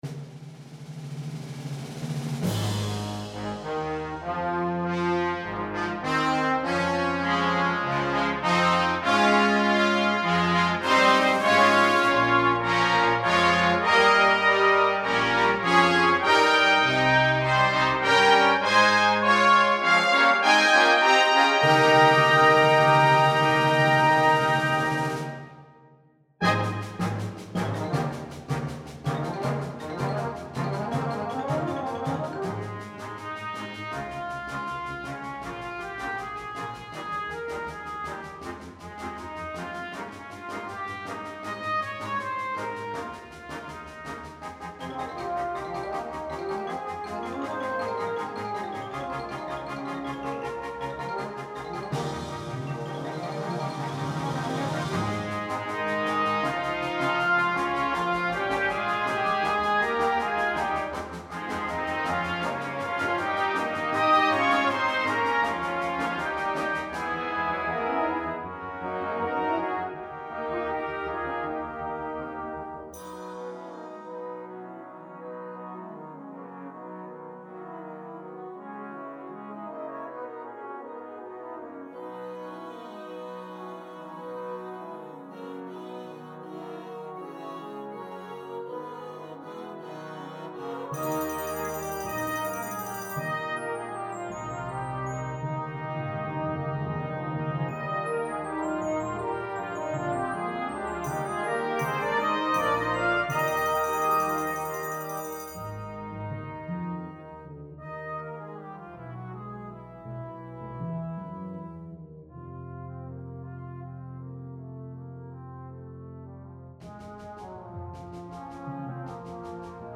Besetzung: Brass Band